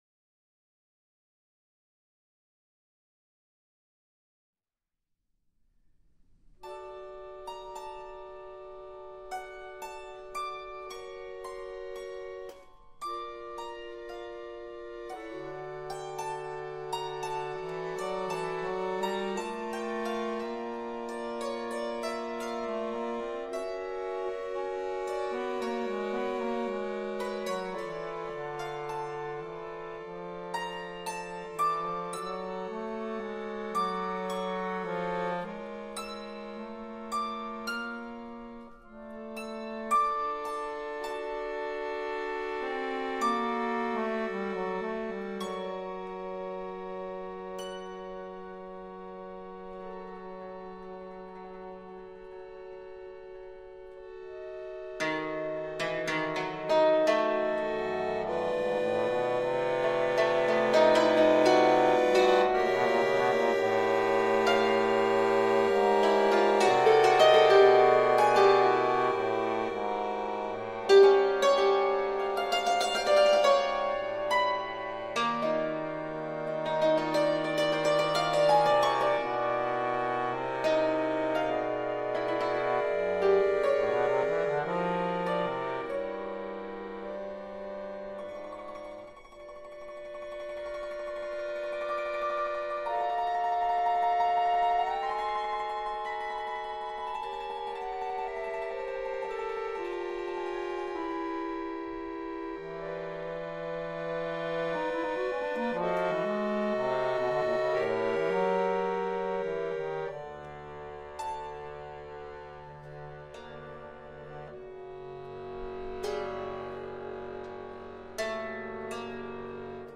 für Tenorhackbrett + Akkordeon
Hackbrett